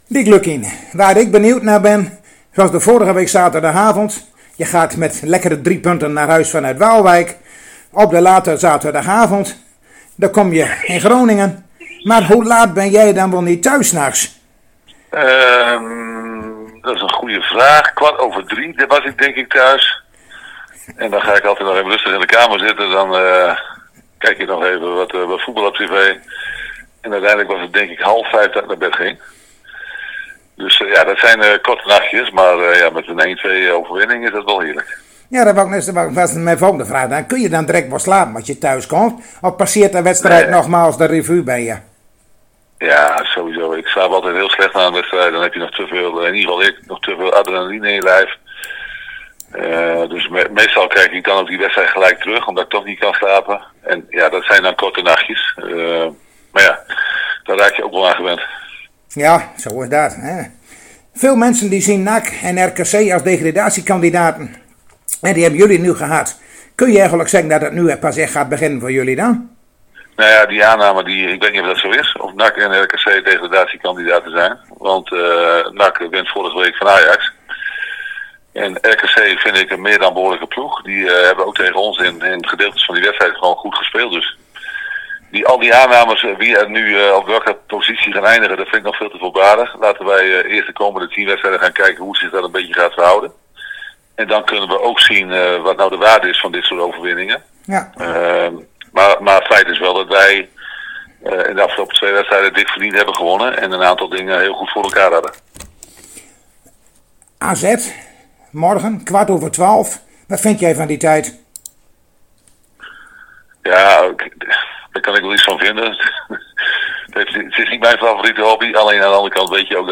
Zojuist spraken wij weer met trainer Dick Lukkien ov er de wedstrijd FC Groningen - AZ van zondagmiddag 12.15.